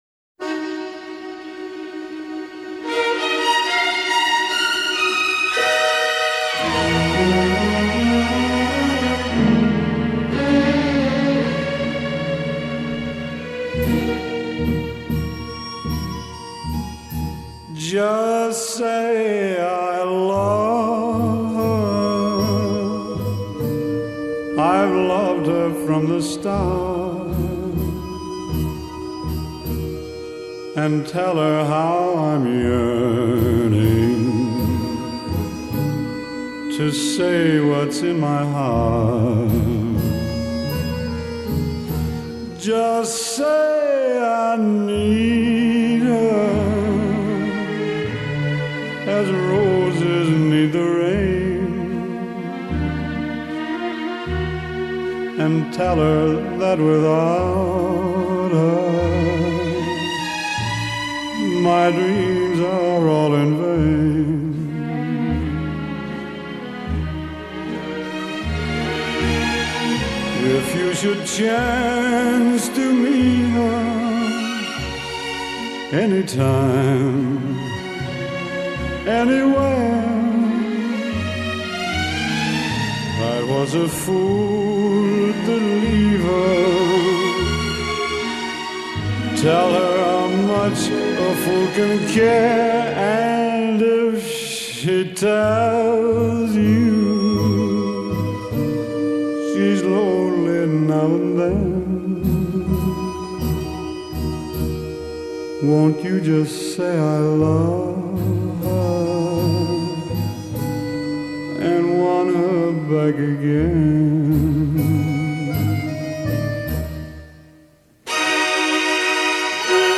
Pop, Classic Pop